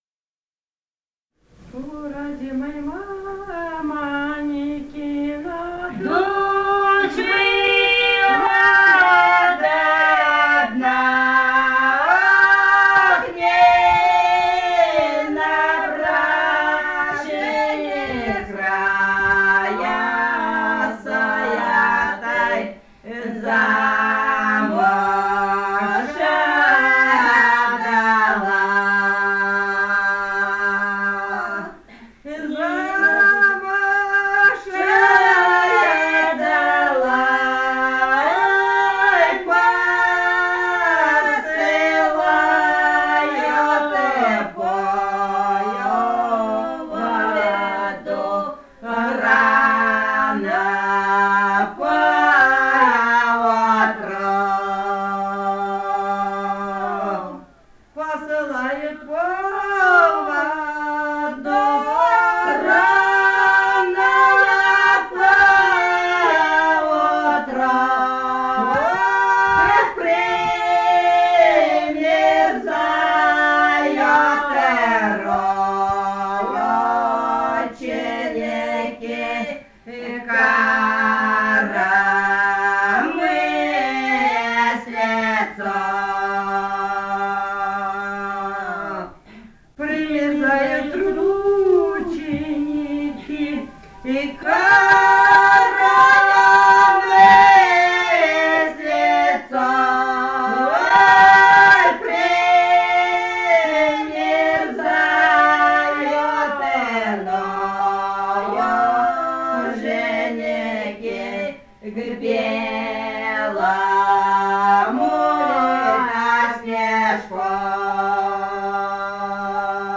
полевые материалы
Бурятия, с. Петропавловка Джидинского района, 1966 г. И0903-12